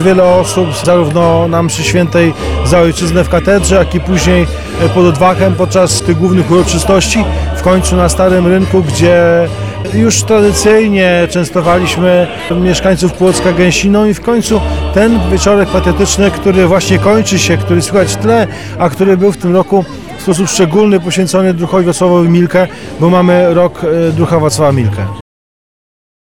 Mówi Prezydent Miasta Płocka Andrzej Nowakowski.